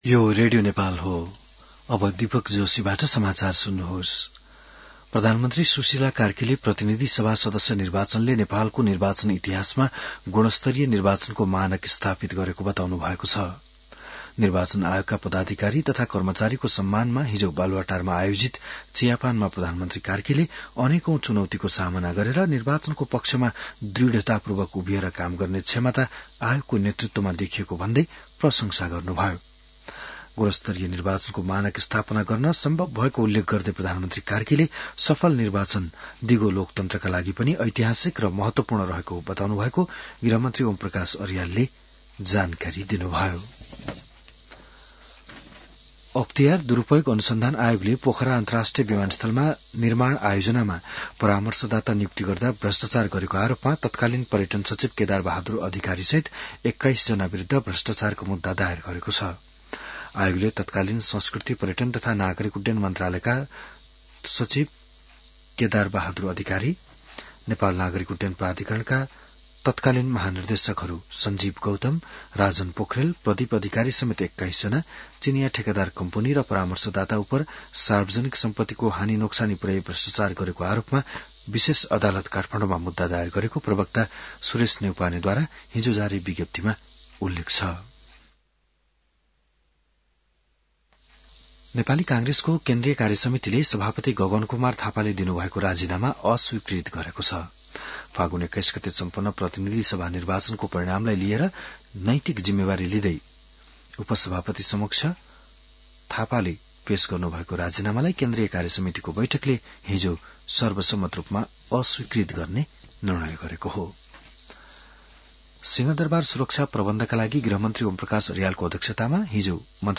बिहान १० बजेको नेपाली समाचार : ९ चैत , २०८२